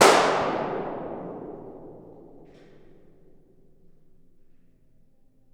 Bubble Pop 3 Sound Effect Free Download
Bubble Pop 3